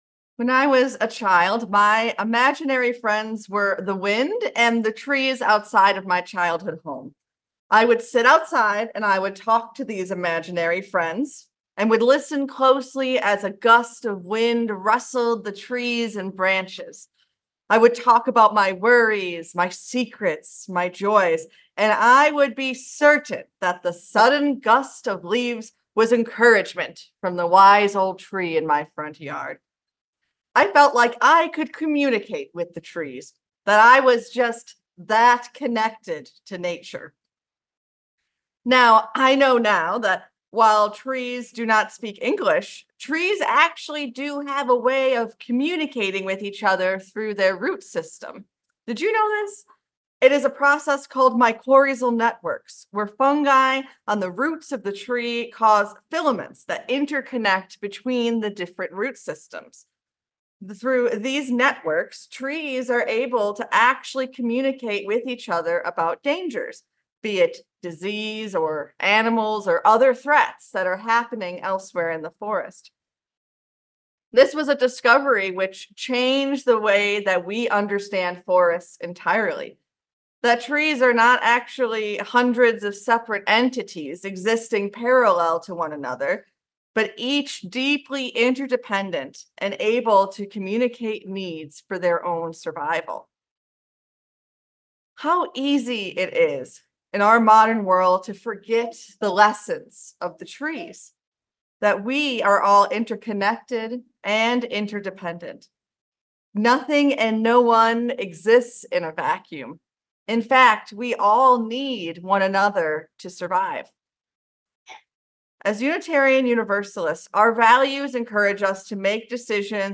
Today, we’ll learn about the spiritual practice of generosity, and members of our Stewardship Team will show us concrete ways to put that into practice, during and after the service. We’ll also welcome new members in a Joining Ceremony and hear music from our Folk Group.